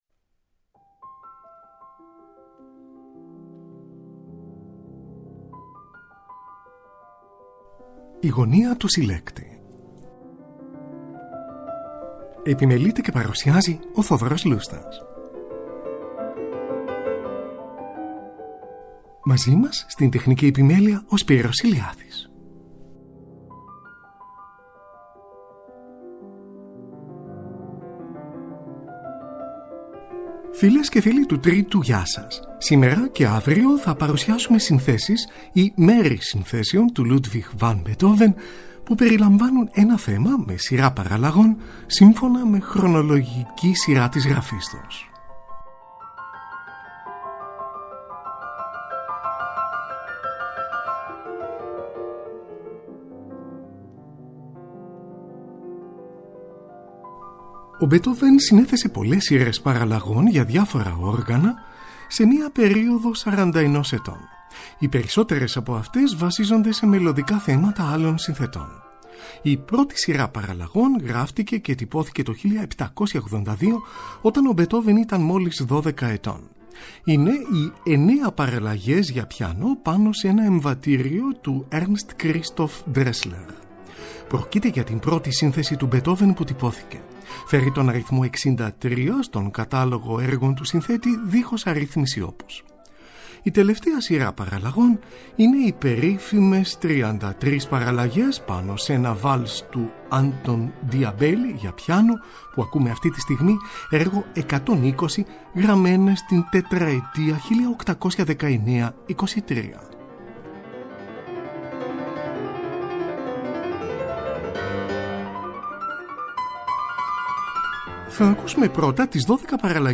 12 παραλλαγές για βιολοντσέλο και πιάνο, πάνω στο χορωδιακό See, theConqu’ringHeroComes!, από το ορατόριο Ιούδας Μακκαβαίος του Georg Friedrich Händel, WoO 45.
12 παραλλαγές για πιάνο
Σονάτα για βιολί και πιάνο, αρ.1, έργο 12 αρ.1: δεύτερο μέρος (Θέμα και παραλλαγές).
Παίζει ο 84χρονος Wilhelm Backhaus, από ζωντανή ηχογράφηση, στο πλαίσιο του Φεστιβάλ του Salzburg. 10 Αυγούστου 1968.